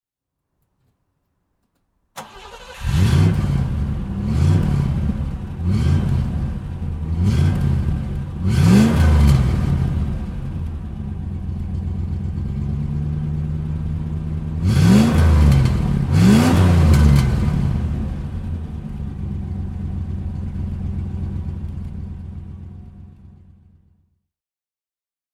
Rover 3500 V8 (1970) - Starten und Leerlauf